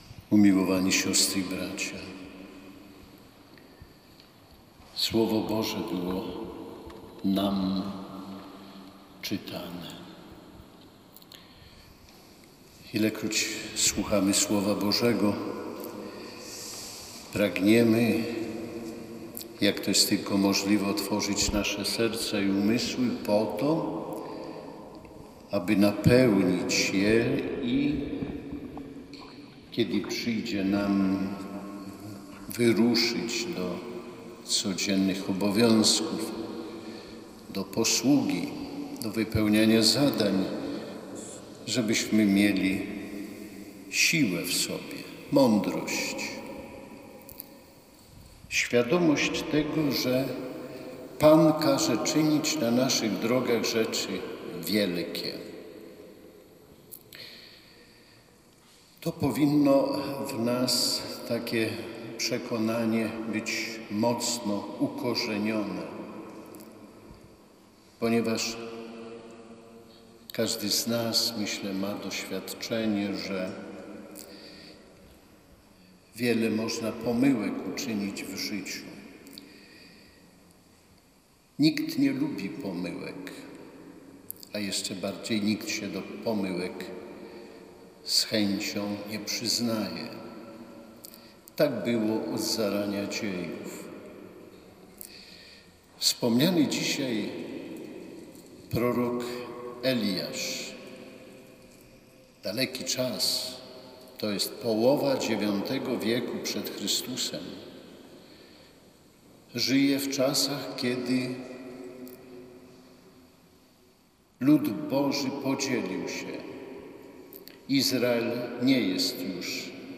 O mądrość i odpowiedzialność modlili się samorządowcy diecezji warszawsko-praskiej. Spotkali się podczas Mszy Świętej odprawionej przez biskupa warszawsko-praskiego Romualda Kamińskiego w Sanktuarium świętego ojca Pio na Gocławiu.
Cała homilia: